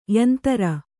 ♪ yantara